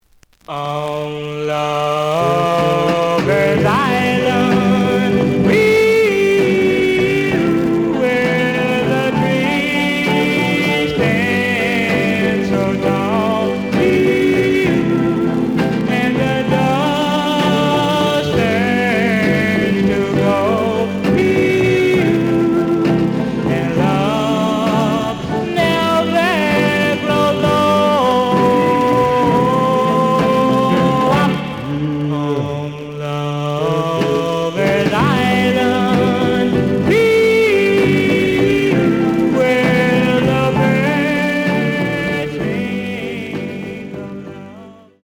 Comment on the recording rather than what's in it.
The audio sample is recorded from the actual item. ●Format: 7 inch Slight noise on A side.)